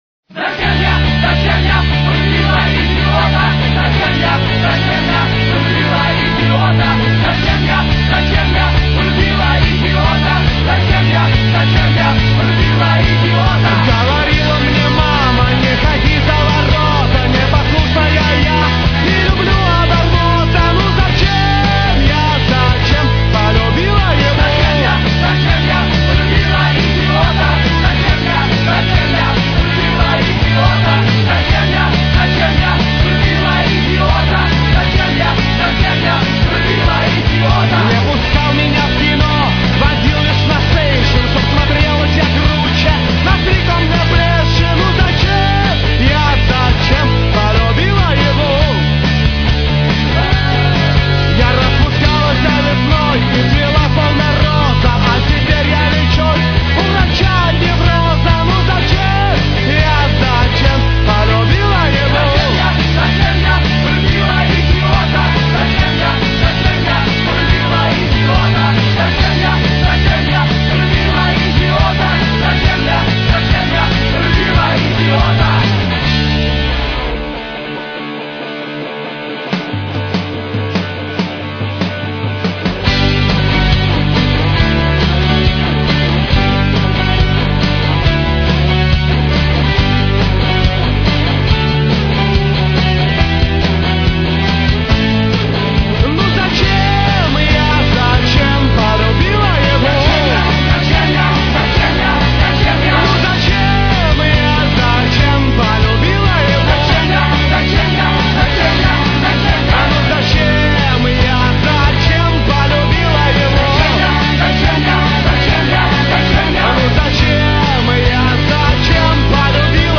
ПАНК ВЫСЩЕЙ КАТЕГОРИИ!!!